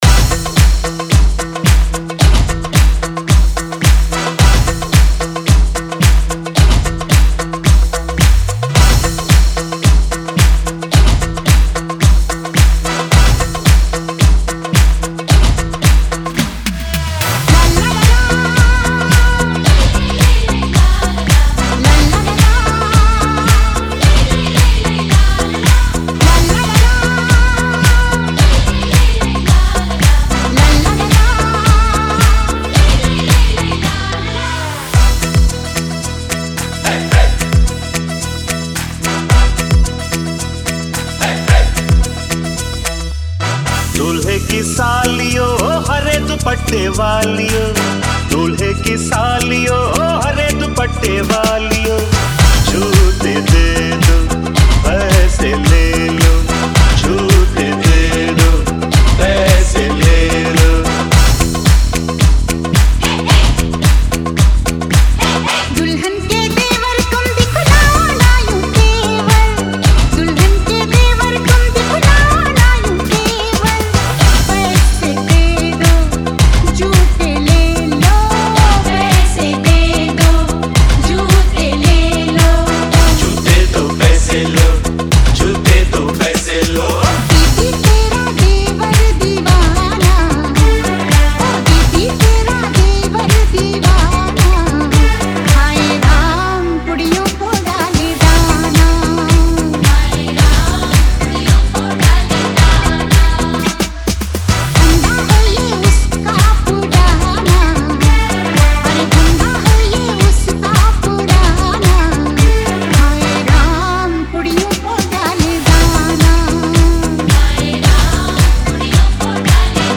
WEDDING MIX